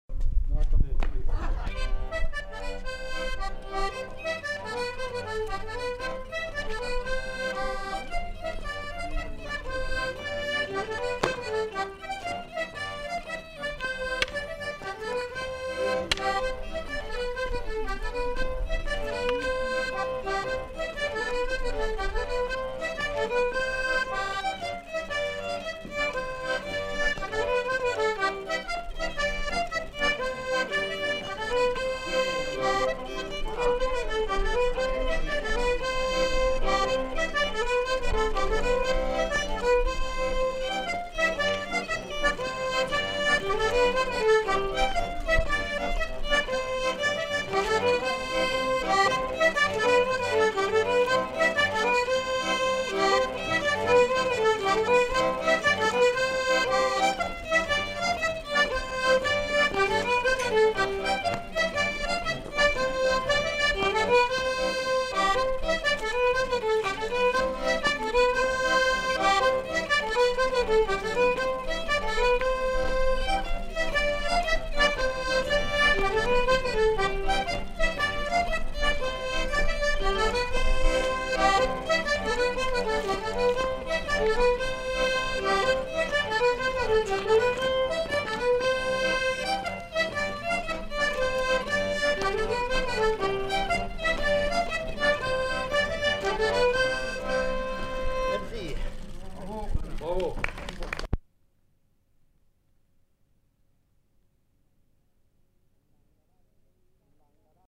Rondeau
Aire culturelle : Savès
Lieu : Sauveterre
Genre : morceau instrumental
Instrument de musique : violon ; accordéon diatonique
Danse : rondeau